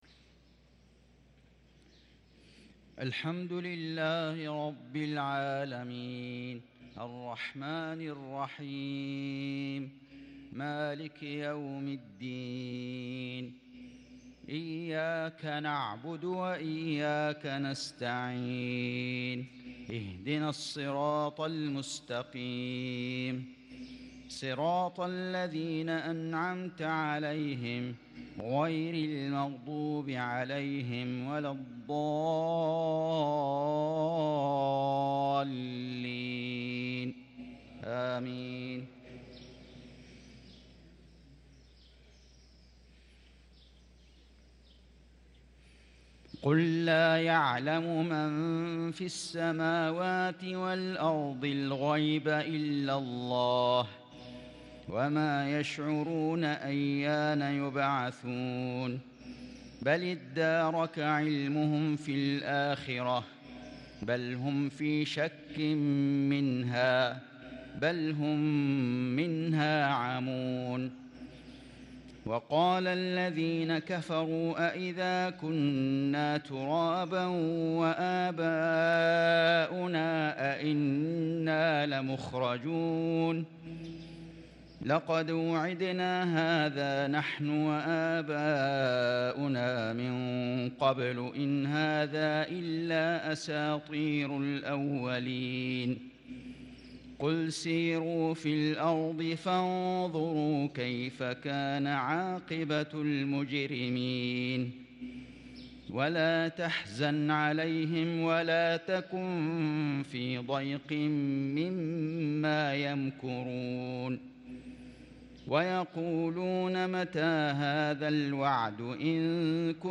مغرب ٥-٨ -١٤٤٣هـ سورة النمل | Maghrib prayer from suarh an-Naml 8-3-2022 > 1443 🕋 > الفروض - تلاوات الحرمين